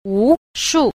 6. 無數 – wúshù – vô số